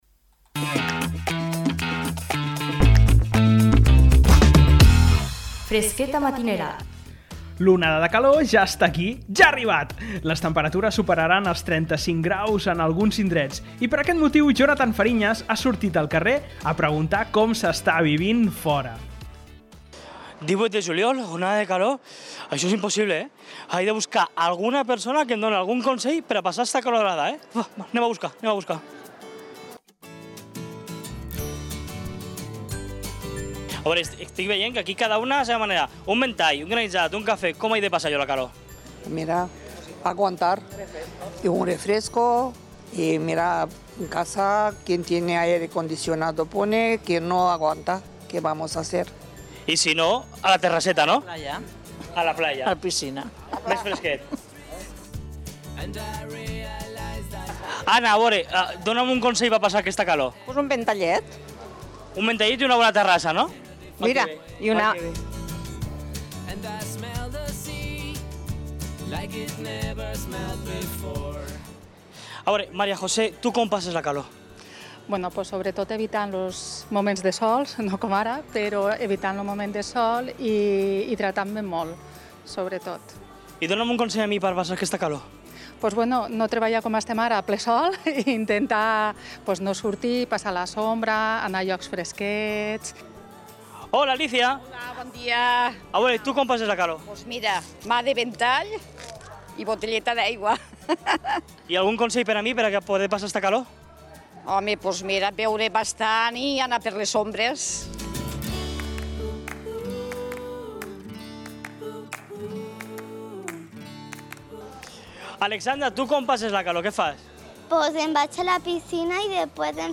ha sortit al carrer a preguntar com s’està vivint a Tortosa.